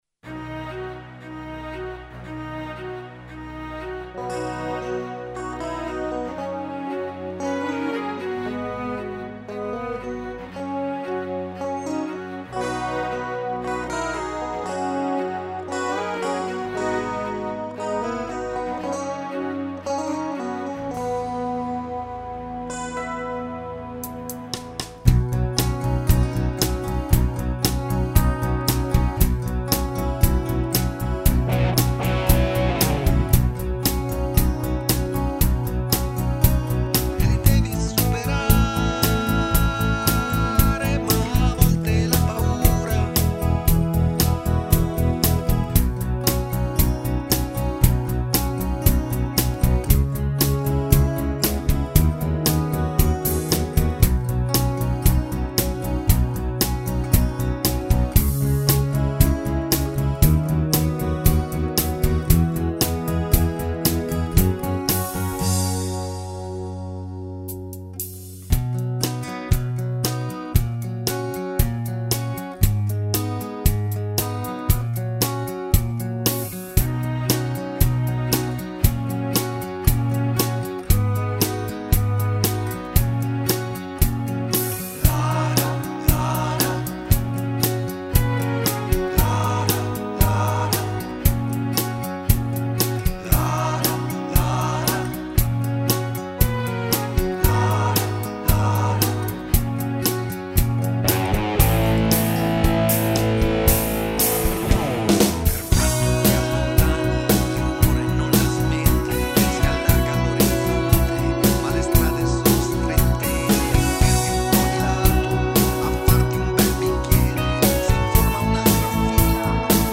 Basi